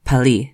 Note: the stress in toki pona falls on the first syllable of words. In some of the recordings it is misplaced.